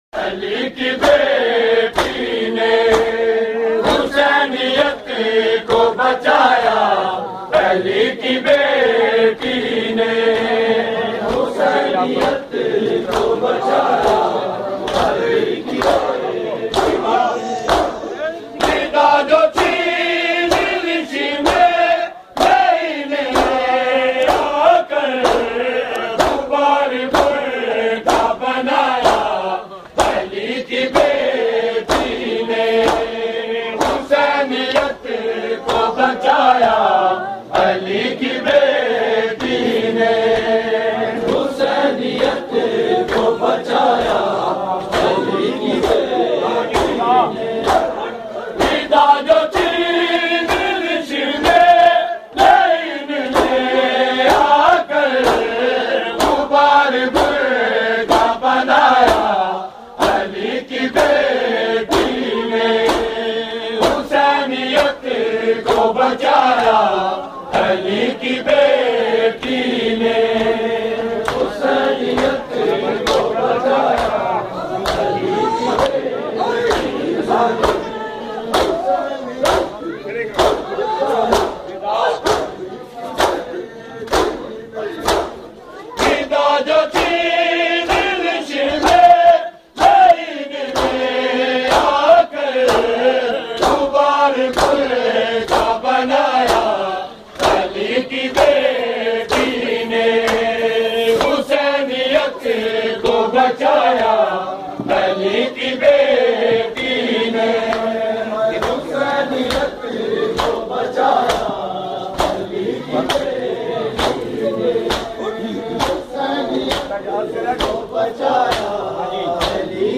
Markazi Matmi Dasta, Rawalpindi
Recording Type: Live